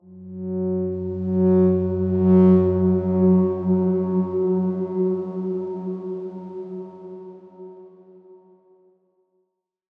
X_Darkswarm-F#2-pp.wav